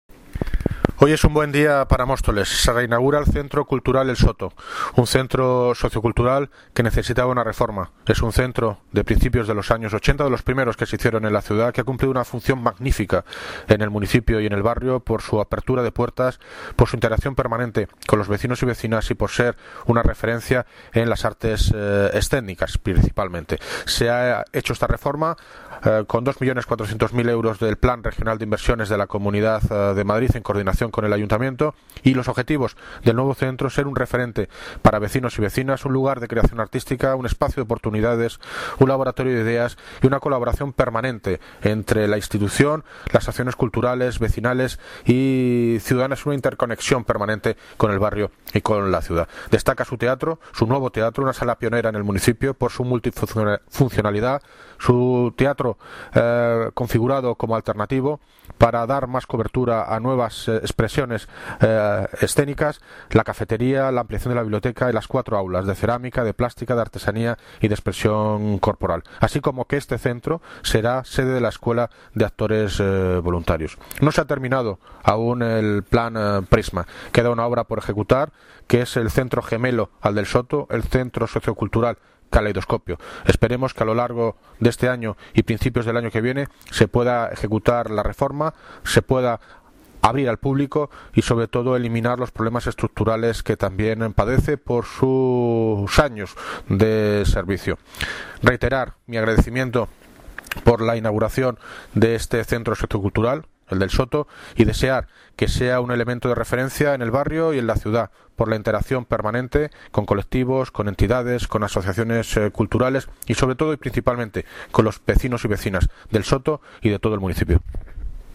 David Lucas (Alcalde) sobre el centro sociocultural El Soto